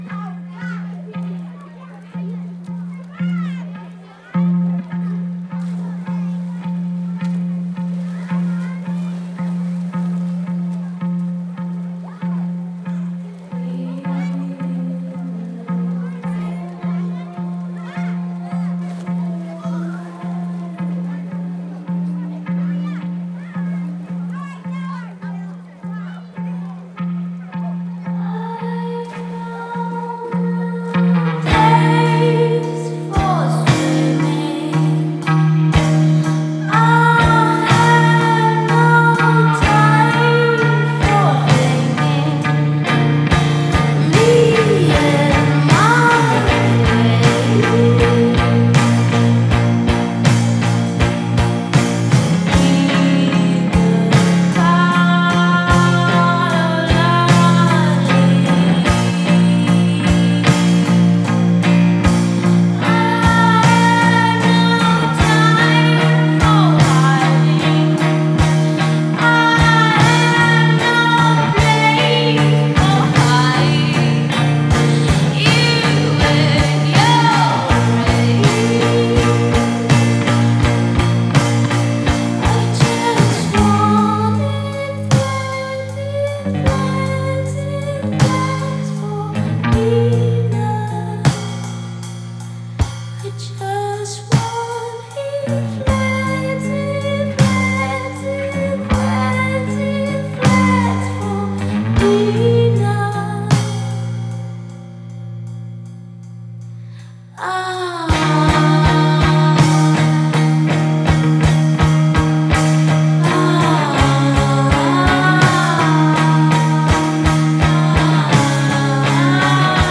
Intervista ai Solki 8-5-2017 | Radio Città Aperta